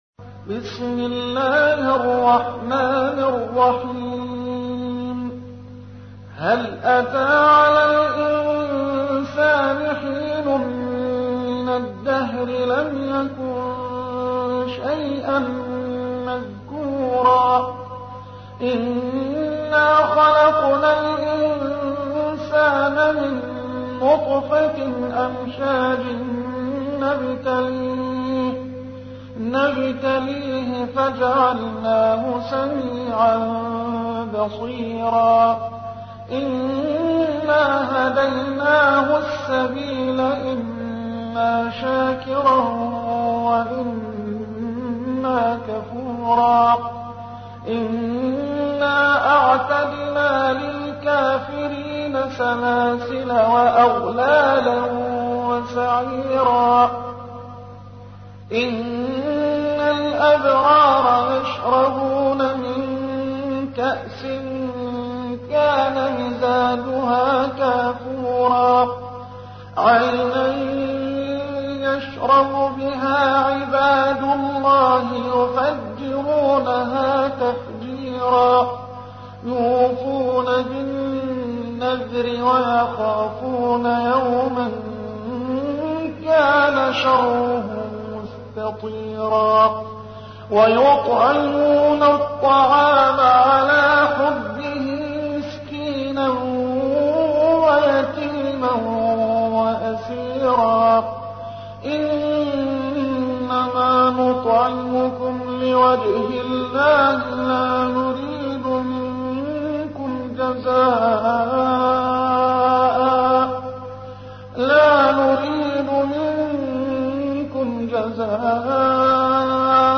تحميل : 76. سورة الإنسان / القارئ محمد حسان / القرآن الكريم / موقع يا حسين